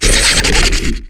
ryuchiChitter1.wav